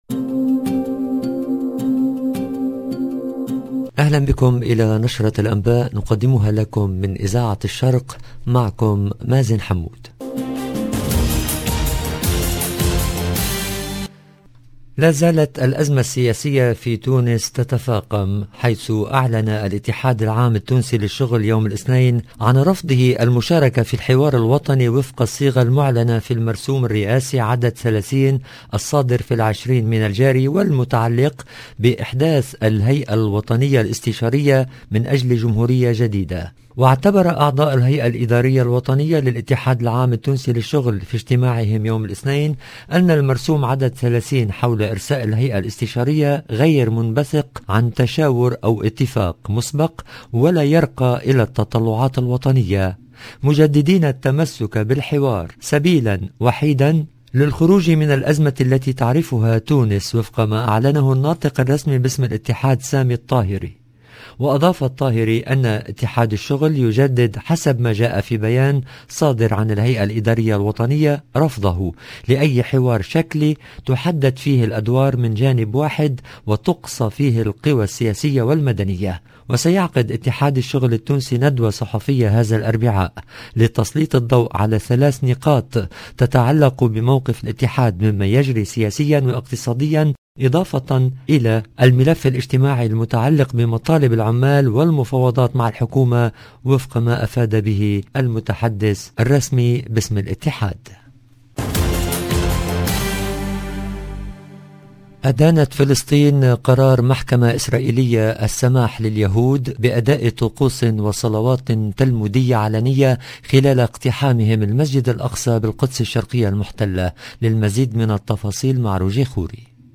LB JOURNAL EN LANGUE ARABE